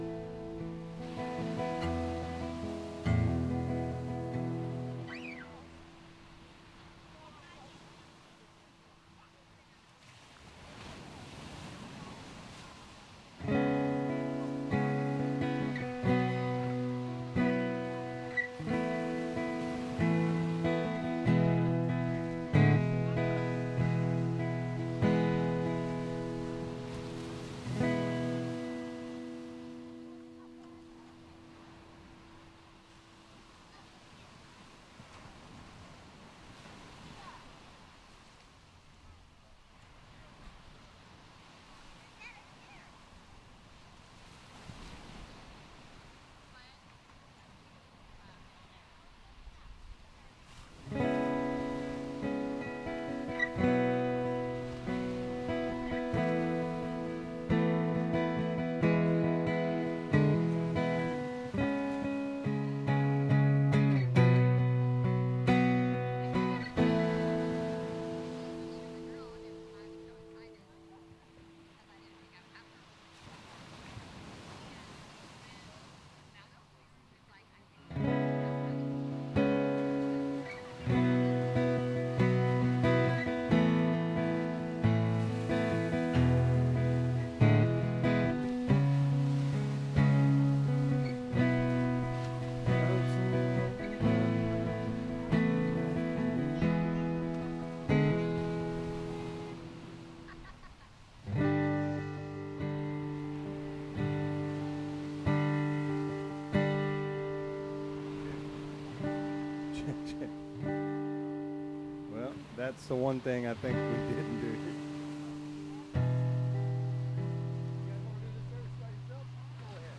SERMON DESCRIPTION God’s truth is not meant to be hidden—it is meant to shine.